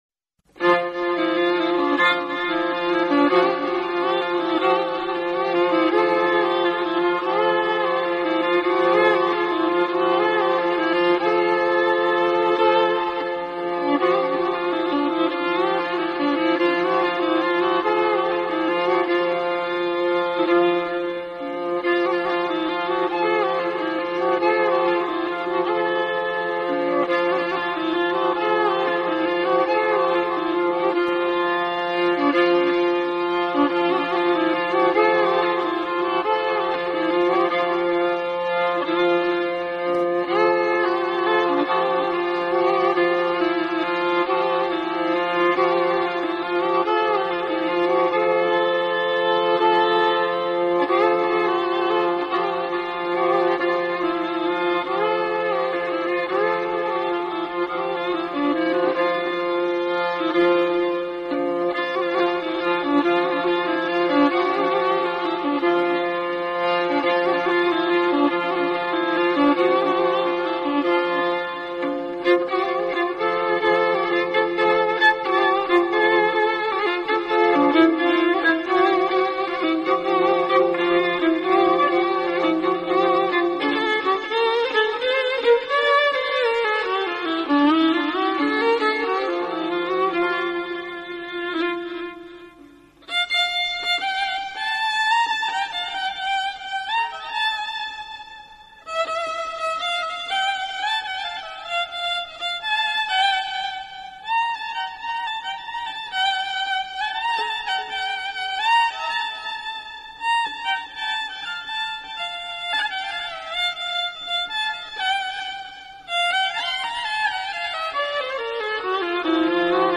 ویولن